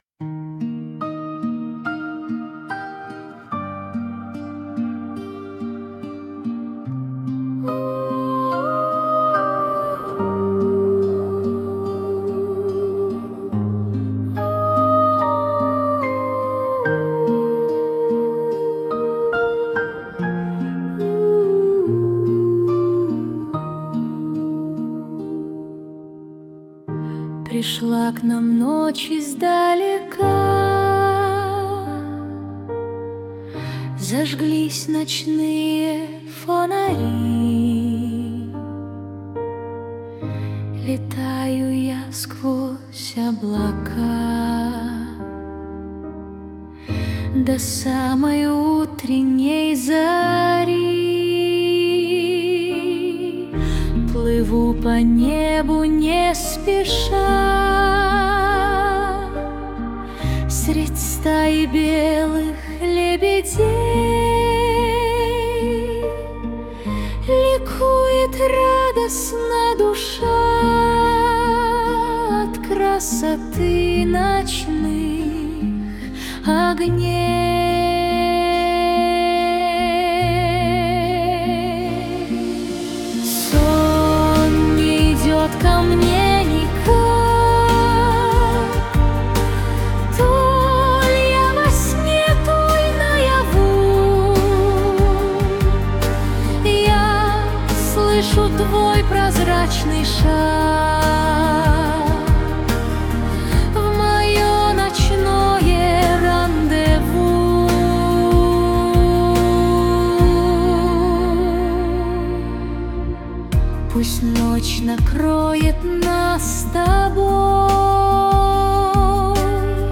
mp3,5520k] AI Generated